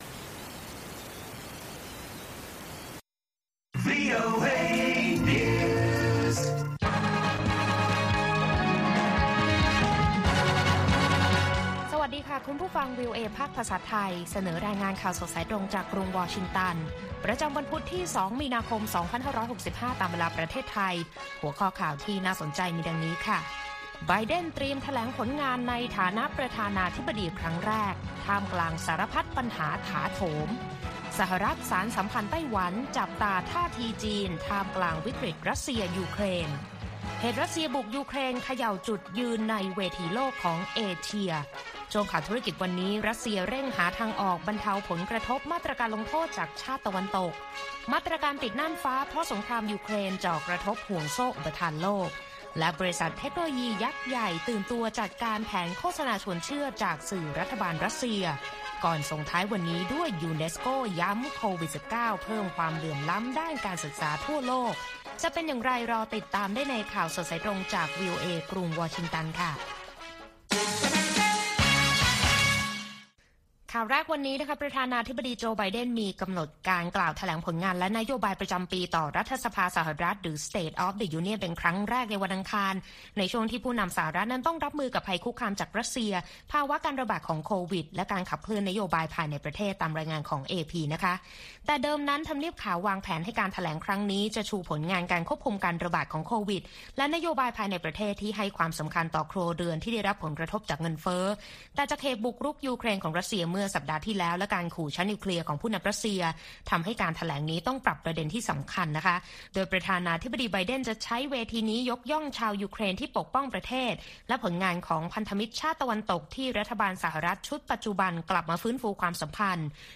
ข่าวสดสายตรงจากวีโอเอ ภาคภาษาไทย 8:30–9:00 น. ประจำวันพุธที่ 2 มีนาคม 2565 ตามเวลาในประเทศไทย